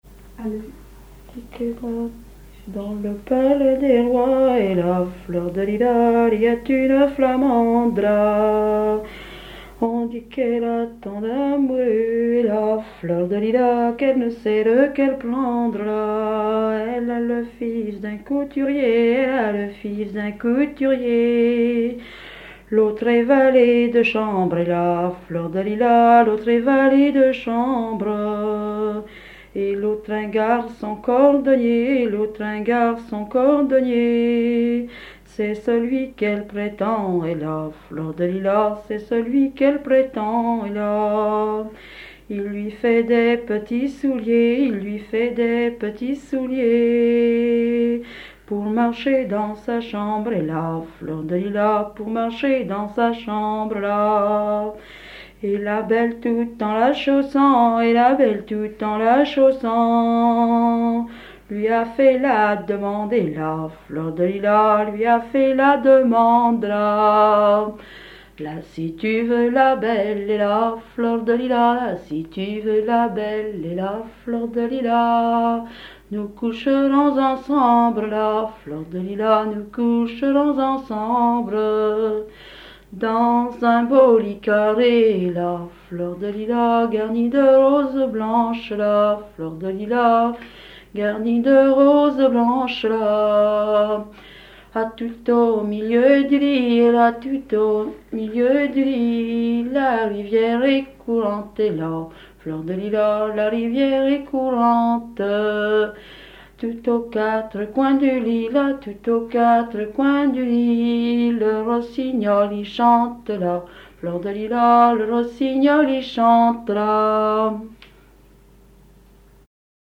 Fonction d'après l'analyste danse : ronde
Chansons traditionnelles
Pièce musicale inédite